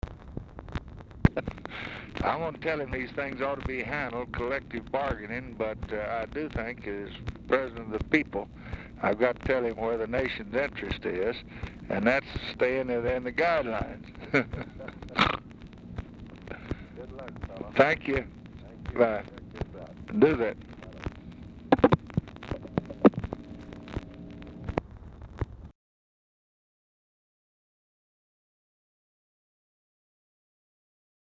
Telephone conversation
POOR SOUND QUALITY
Dictation belt